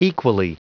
Prononciation du mot equally en anglais (fichier audio)
Prononciation du mot : equally